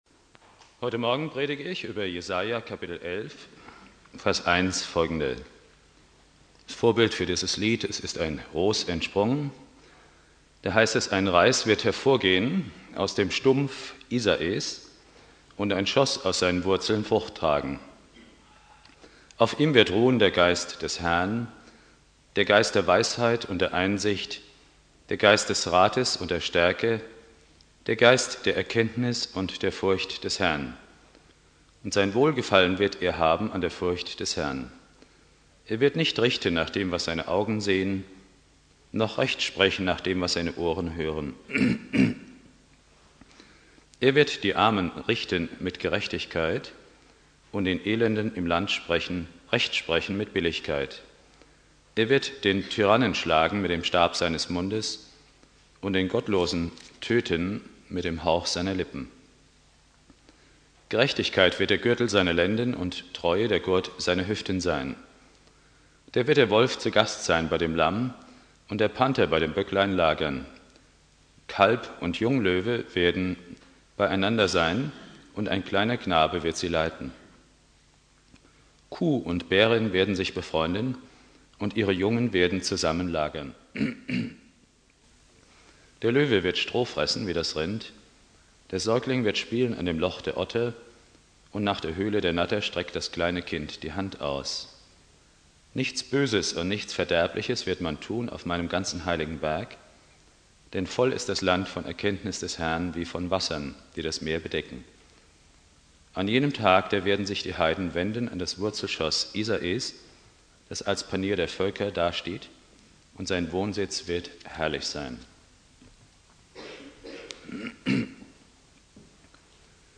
Predigt
2.Weihnachtstag